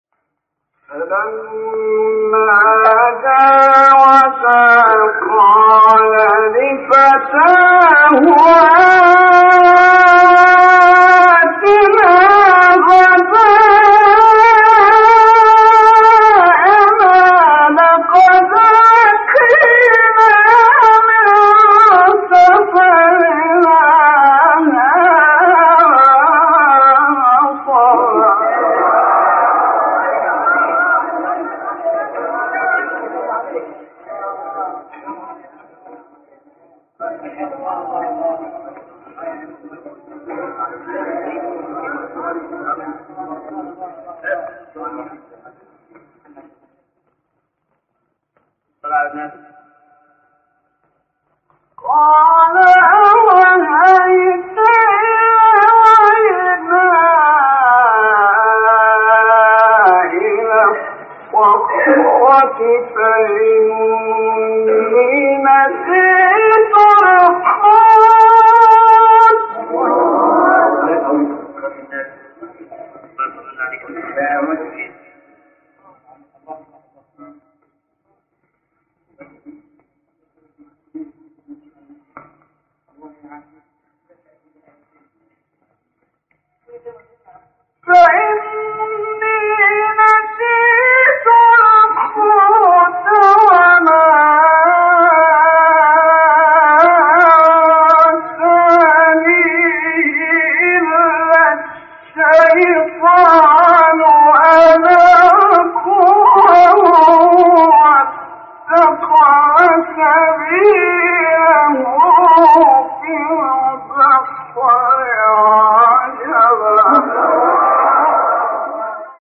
سوره : کهف آیه: 62-63 استاد : محمد رفعت مقام : سه گاه قبلی بعدی